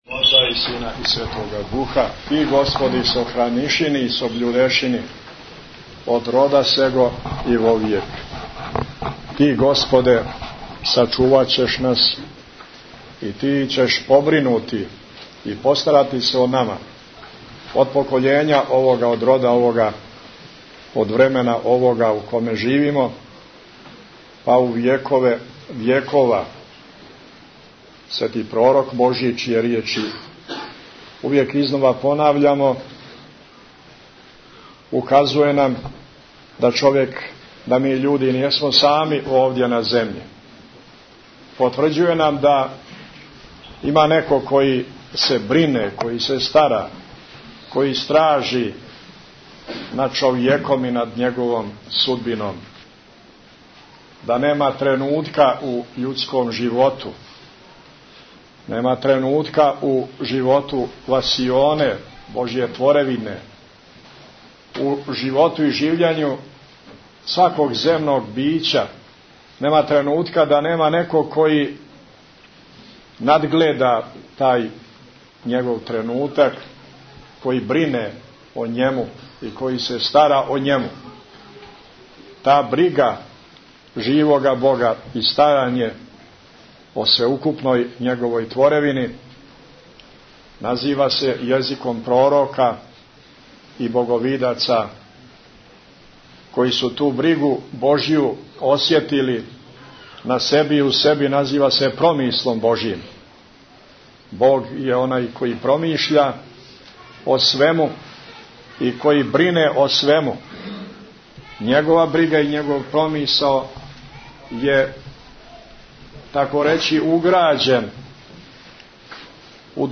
Бесједа Његовог Високопреосвештенства Митрополита Амфилохија са службе Великог бденија у Цетињском манастиру, 24. април 2008
Бесједе